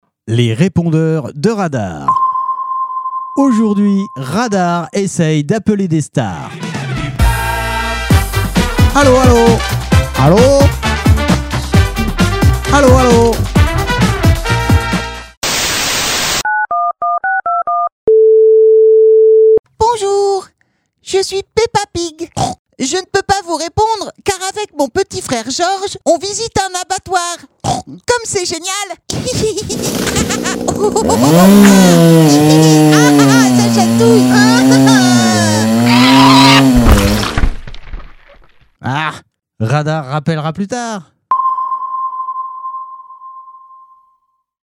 Retrouvez ici les plus belles parodies des répondeurs des stars